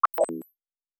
pgs/Assets/Audio/Sci-Fi Sounds/Interface/Data 17.wav at master